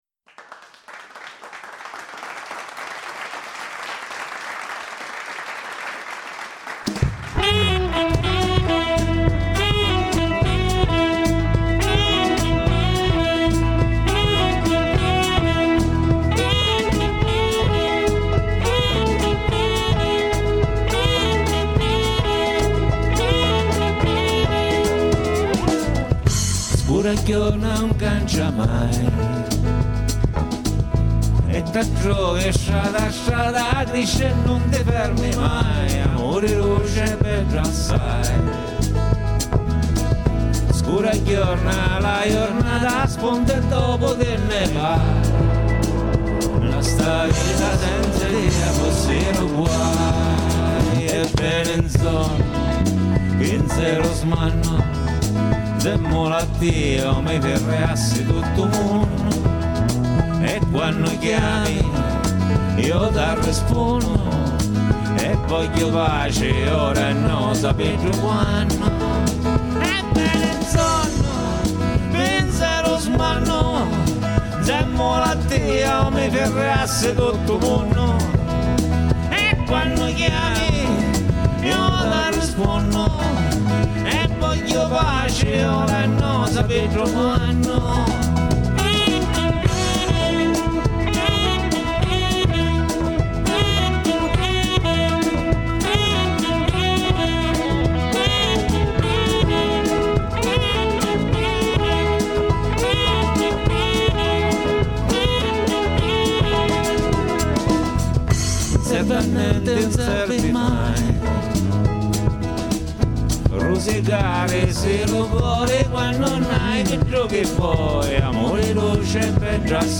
registrata live